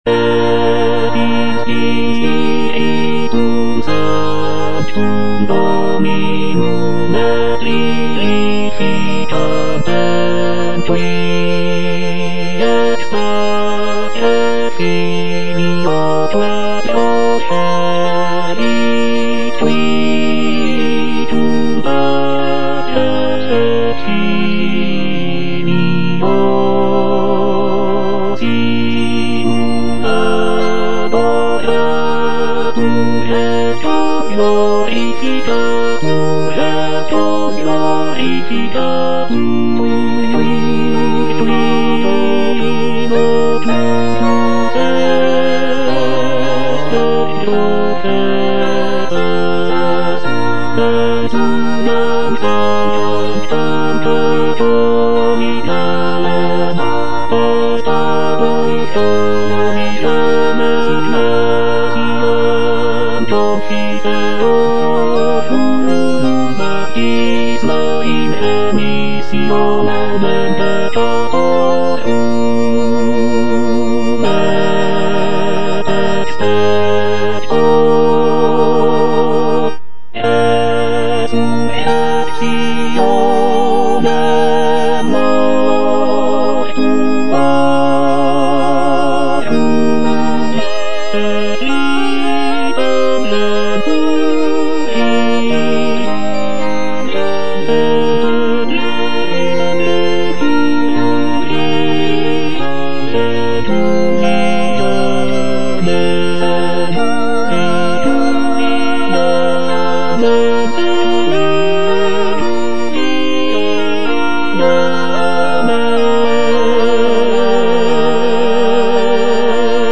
O. DE LASSUS - MISSA PASCHALIS Et in Spiritum Sanctum - Tenor (Emphasised voice and other voices) Ads stop: auto-stop
The mass is written for four voices, typically SATB (soprano, alto, tenor, bass), and follows the traditional structure of the Catholic mass.